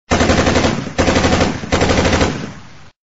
Download Machine Gun sound effect for free.
Machine Gun